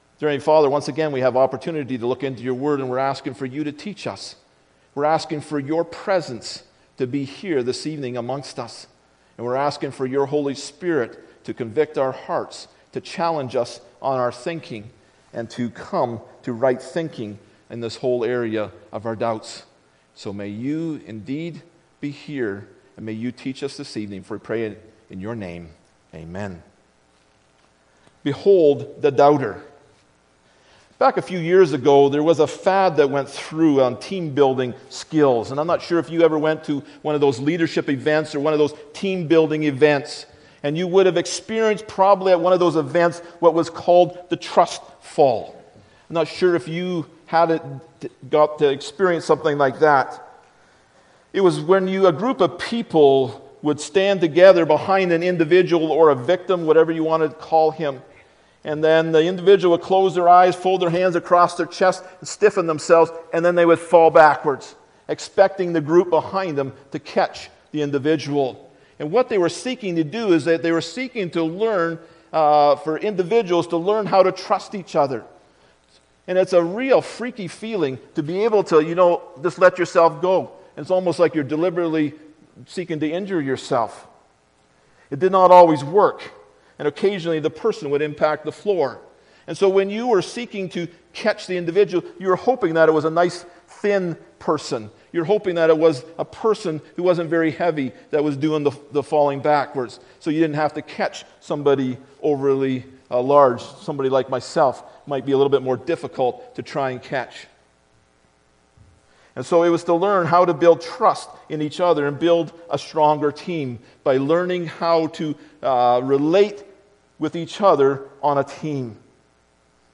Tough Questions Service Type: Sunday Morning Preacher